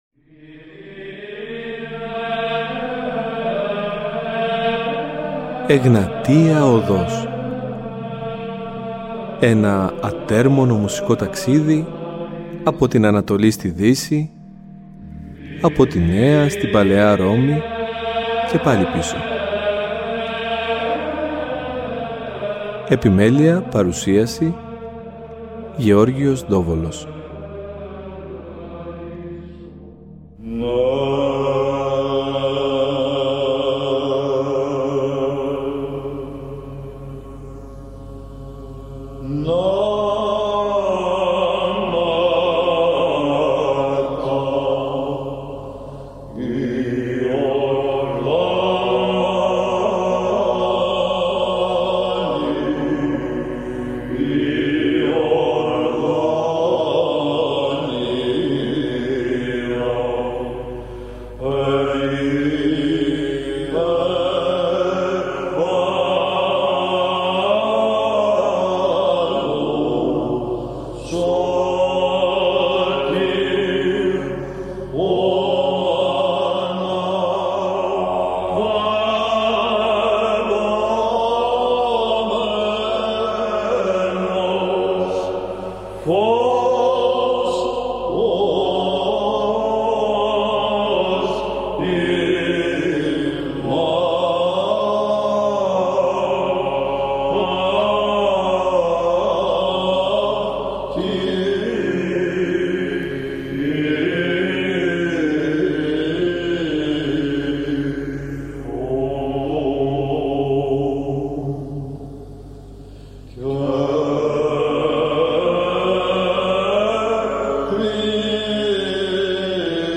Ακούστε το 1ο από τα δύο μέρη στην εκπομπή που μεταδόθηκε το Σάββατο 11 Ιανουαρίου.